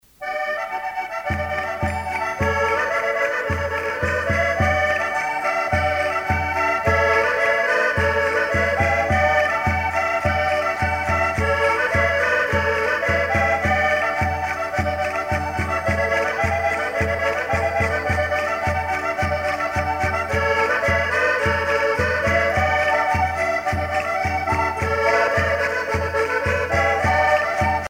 danse : vira (Portugal)
Pièce musicale éditée